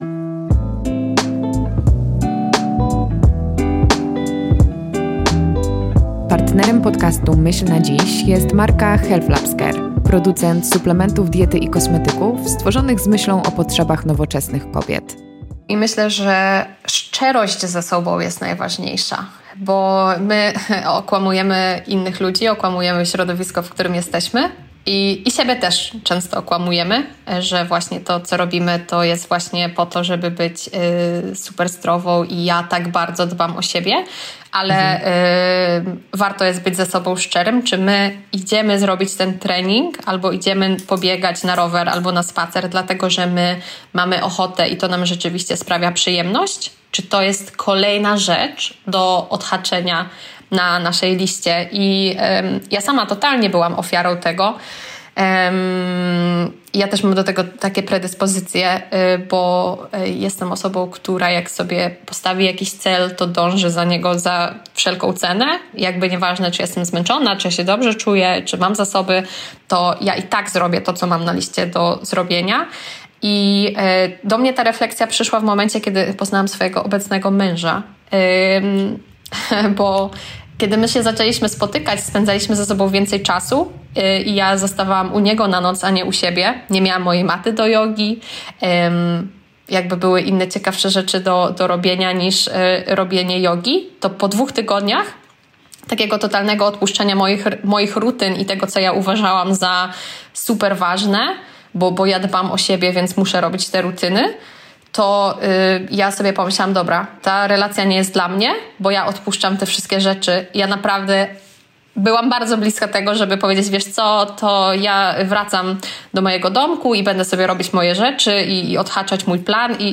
Fragment rozmowy